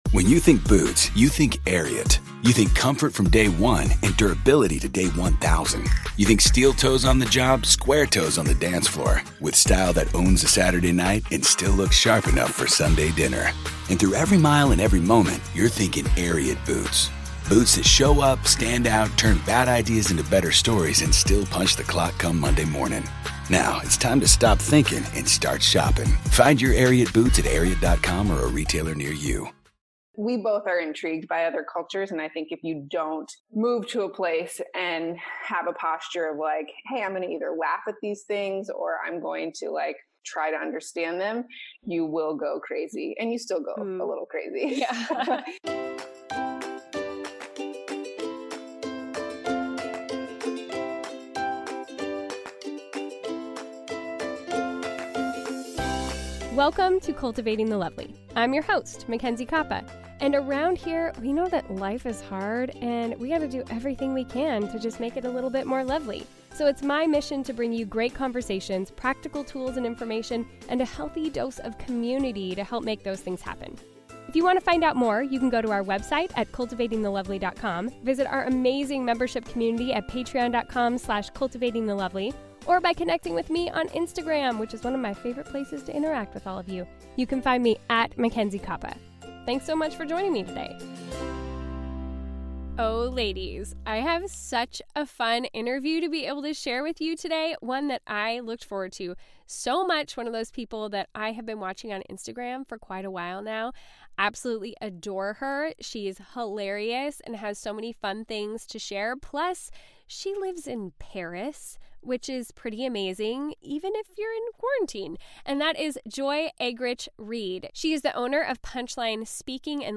So sit back, relax and enjoy this lively conversation!